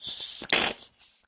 Звук воды из микро клизмы